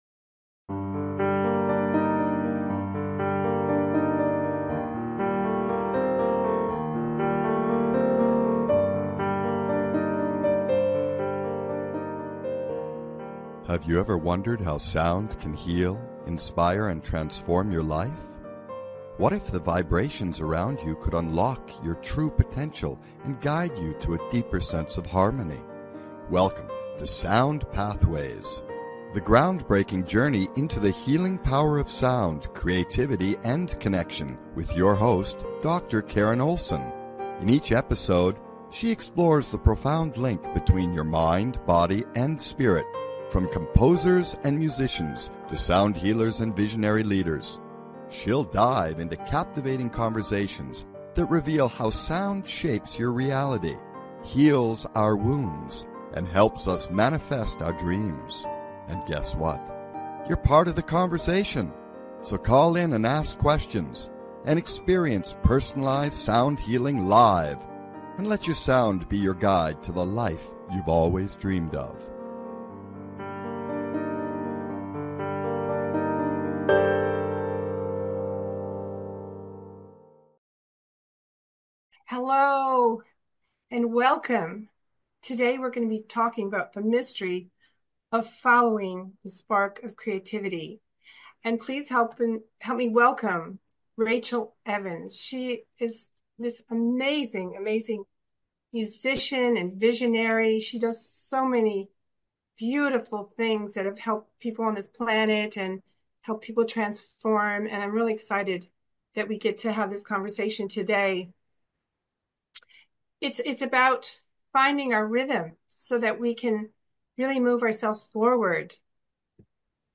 In this powerful conversation, we explore music, movement, and the magic of personal expansion.